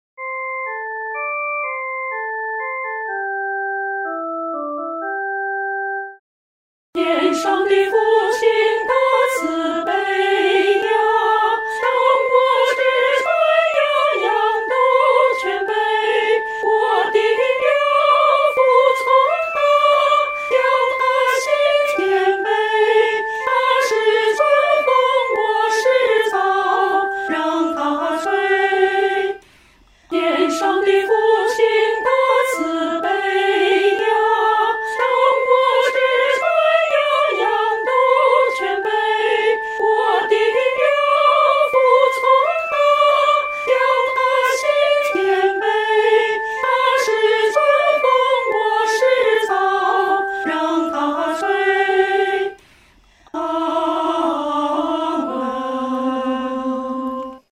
女高
本首圣诗由网上圣诗班 (南京）录制